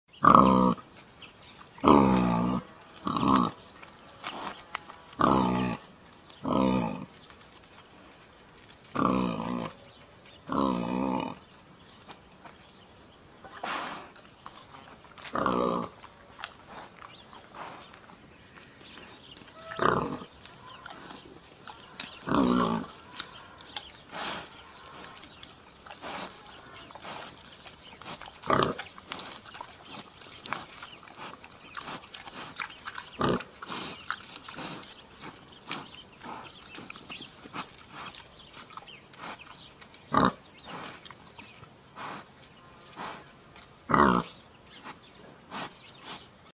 На этой странице собраны натуральные звуки диких кабанов: от хрюканья и рычания до топота копыт по лесу.
Звук кабана в лесной чаще